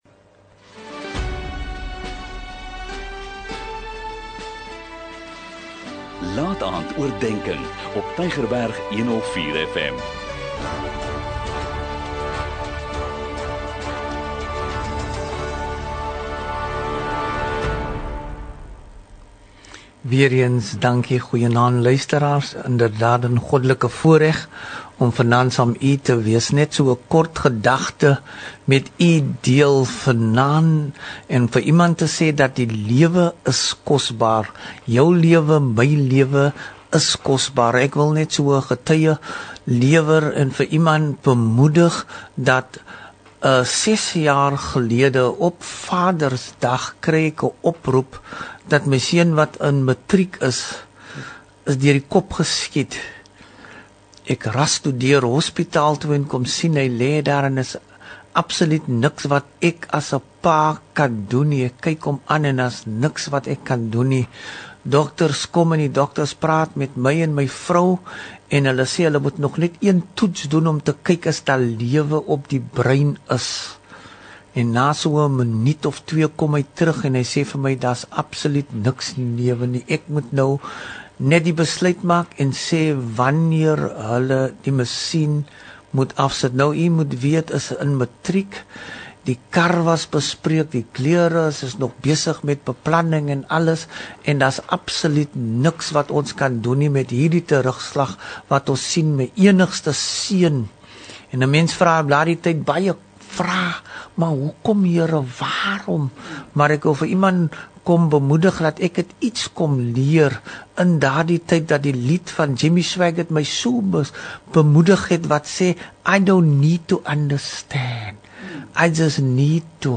n Kort bemoedigende boodskap, elke Sondagaand om 20:45, aangebied deur verskeie predikers.